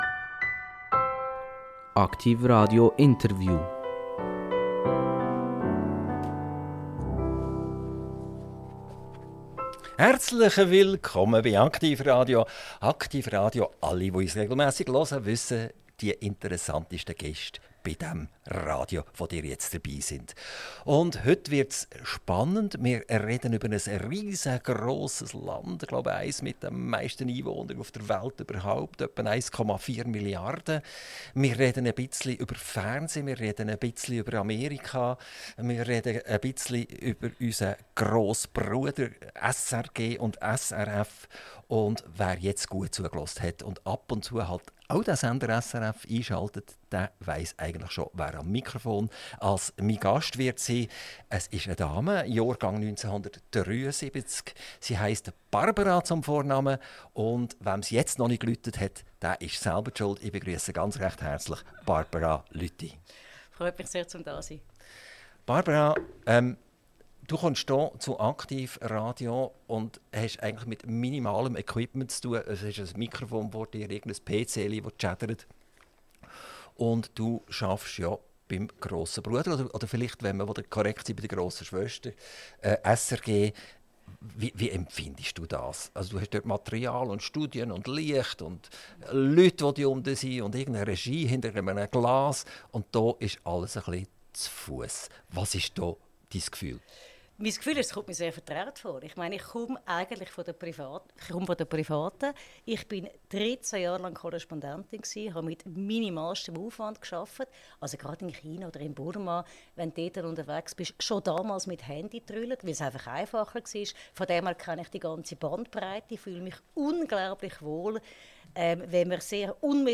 INTERVIEW - Barbara Lüthi - 17.10.2025 ~ AKTIV RADIO Podcast